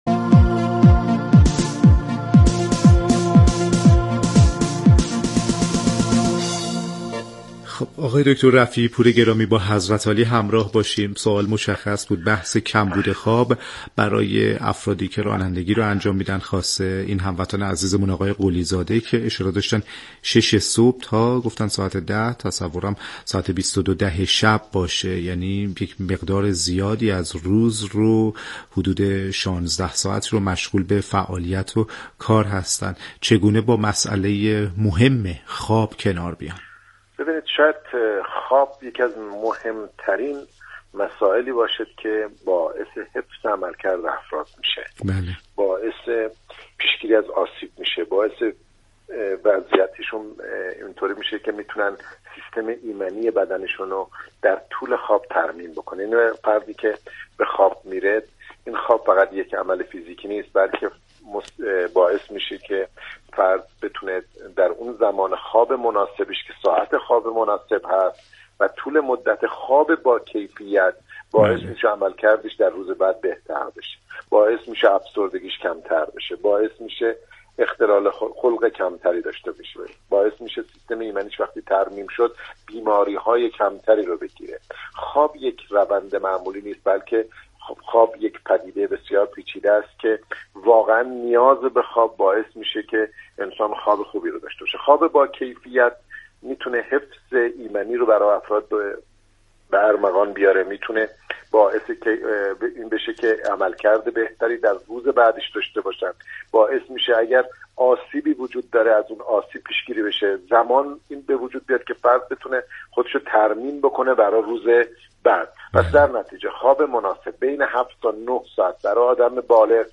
عضو هیات علمی دانشگاه پیام نور در گفت و گو با برنامه نسخه ورزشی رادیو ورزش